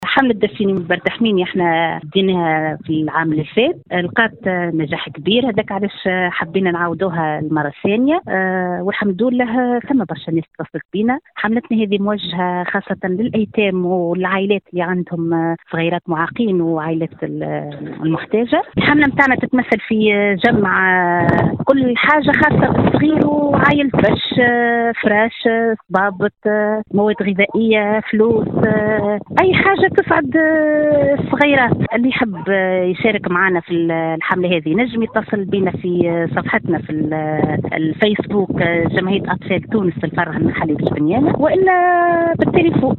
في تصريح ل “ام اف ام”